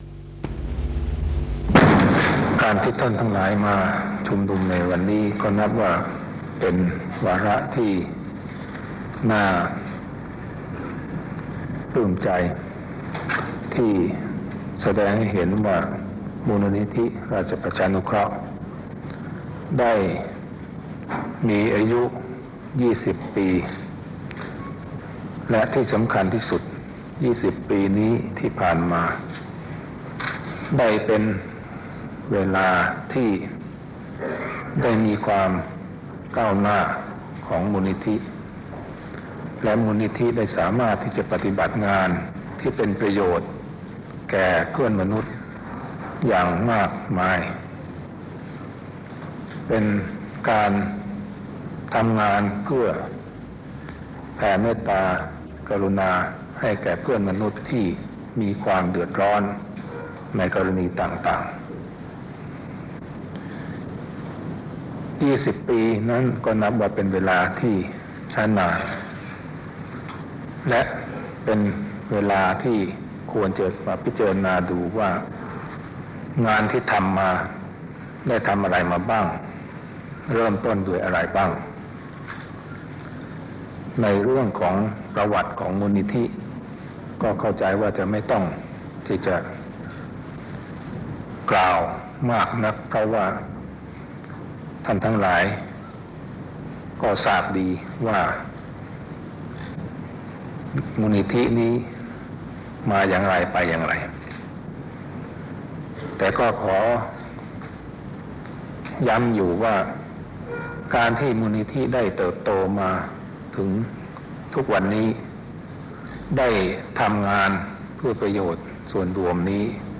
พระบรมราโชวาทพระบาทสมเด็จพระเจ้าอยู่หัวแด่มูลนิธิราชประชานุเคราะห์ ณ ศาลาดุสิตตาลัยฯ 8 สิงหาคม 2526